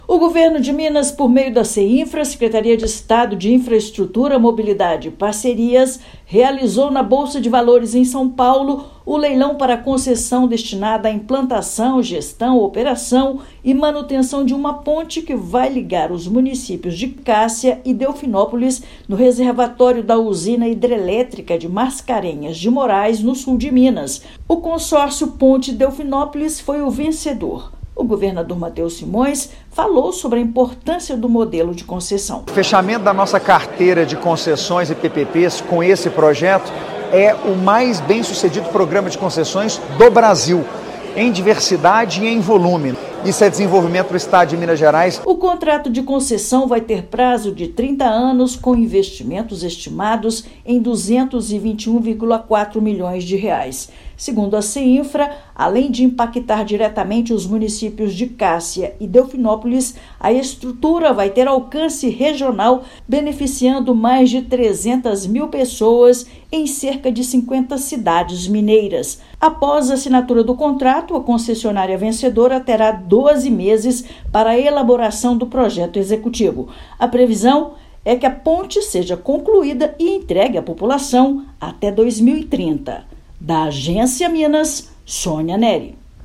Iniciativa terá alcance regional, beneficiando mais de 300 mil pessoas em cerca de 50 municípios mineiros. Ouça matéria de rádio.